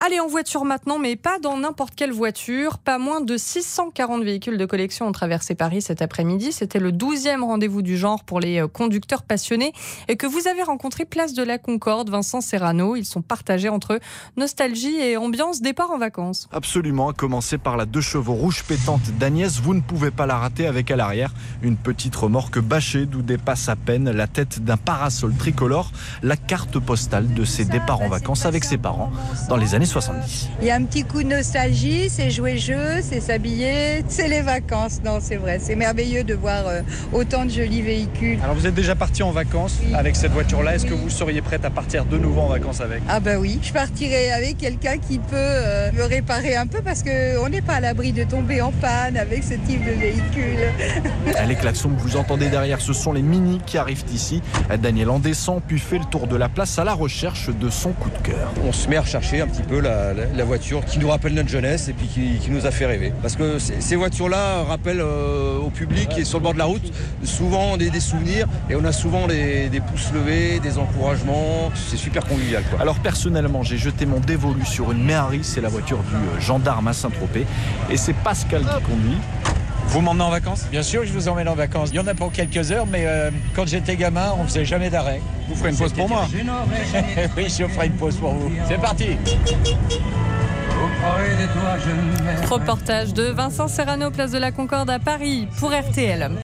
Reportage_Vincennes_en_Anciennes.mp3